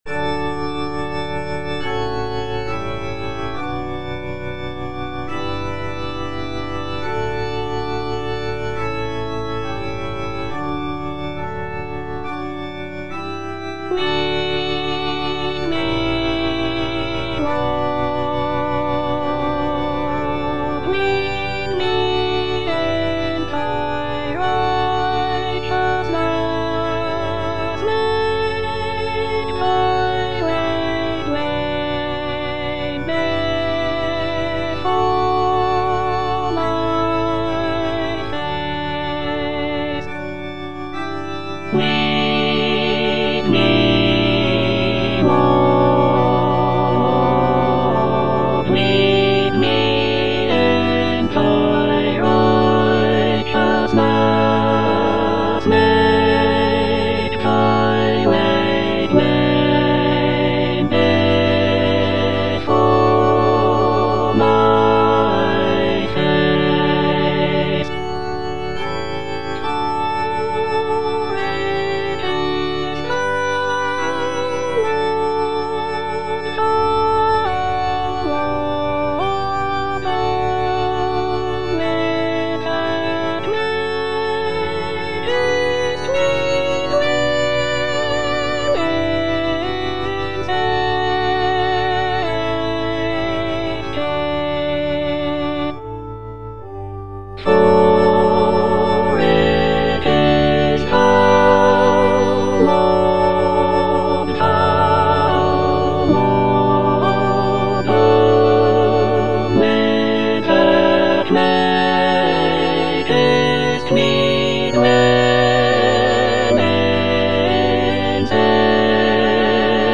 S.S. WESLEY - LEAD ME, LORD (All voices) Ads stop: auto-stop
"Lead me, Lord" is a sacred choral anthem composed by Samuel Sebastian Wesley in the 19th century. This piece is known for its beautiful harmonies and expressive text, which reflects themes of guidance and faith. The music is characterized by lush choral textures and expressive dynamics, making it a popular choice for church choirs and worship services.